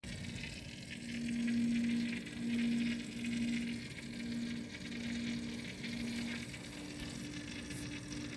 3RSYS SOCOOOL 라니 XY 360 ARGB WHITE-펌프 물 사운드..
어제 헤더에 숫자 값 오류에 대한 글을 올렸고, 오류를 해결하니까 펌프에서 물 소리가 장난아니네요;; 이거 어떻게 해결하나요??
라니 시리즈를 포함한 대부분의 일체형 수냉쿨러는 펌프 작동음과 기포 소리가 발생할 수 있습니다.